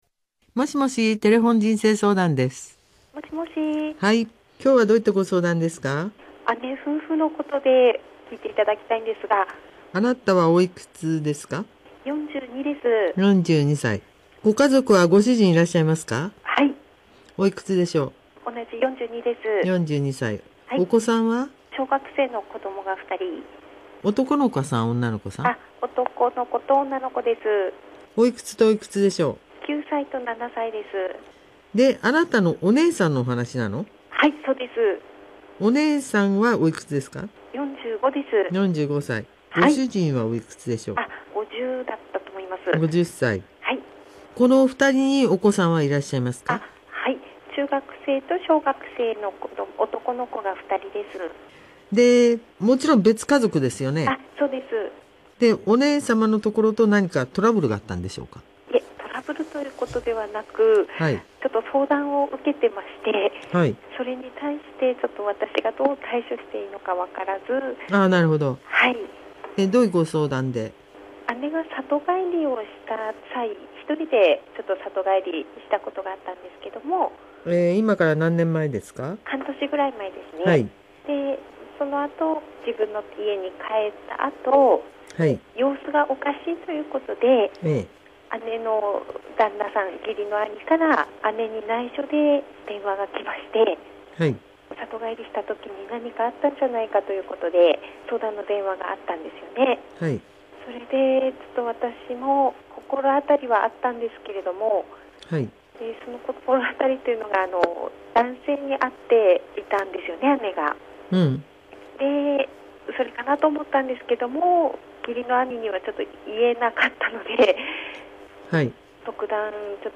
声を弾ませて姉の不倫を心配する妹